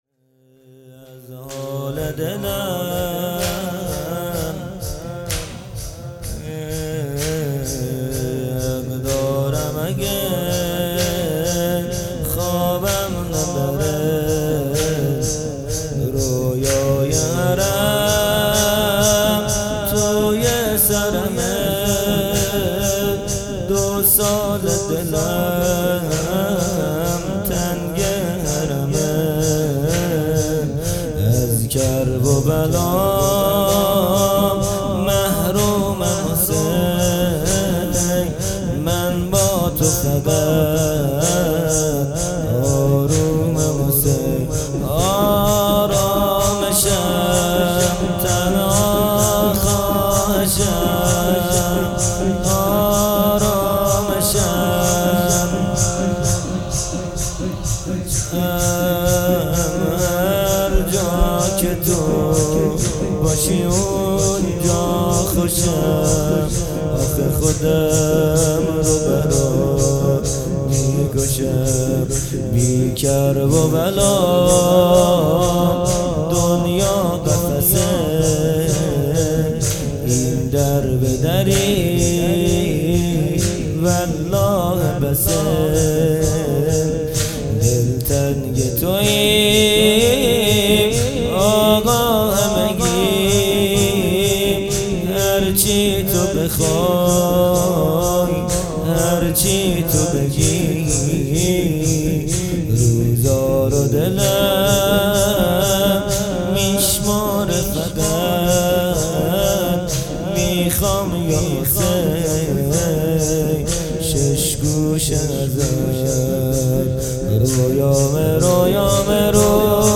جلسه‌ هفتگی